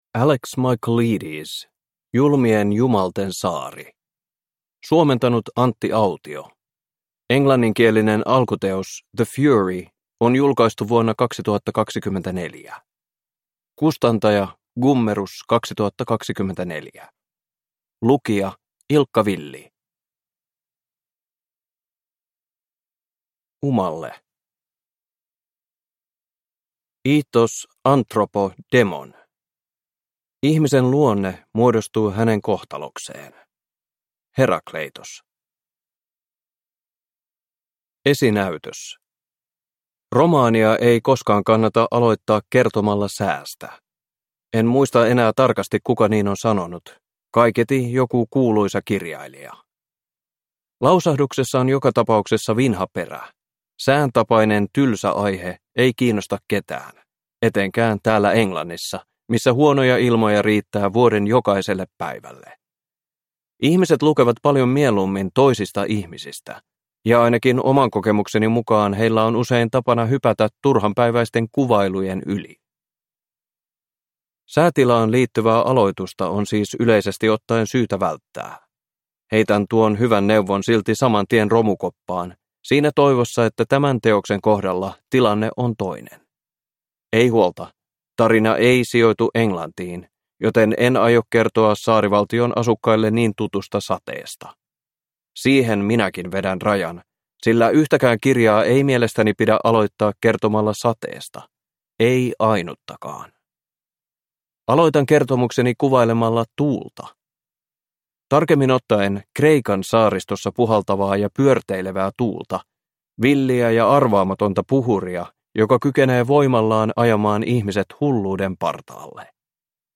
Julmien jumalten saari – Ljudbok